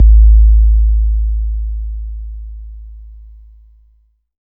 13 808 KICK.wav